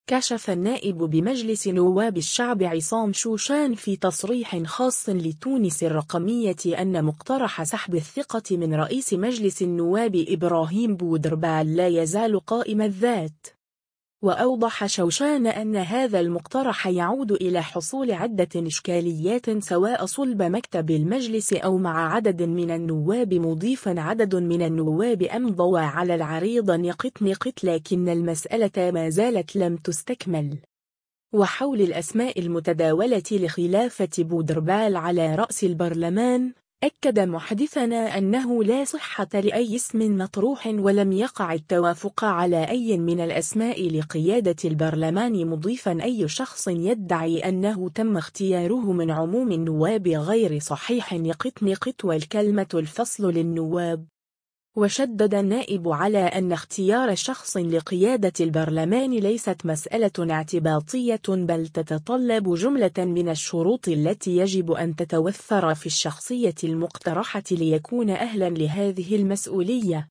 كشف النائب بمجلس نواب الشعب عصام شوشان في تصريح خاص لـ”تونس الرقمية” أن مقترح سحب الثقة من رئيس مجلس النواب ابراهيم بودربالة لا يزال قائم الذات.